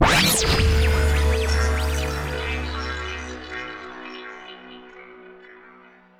teleport.wav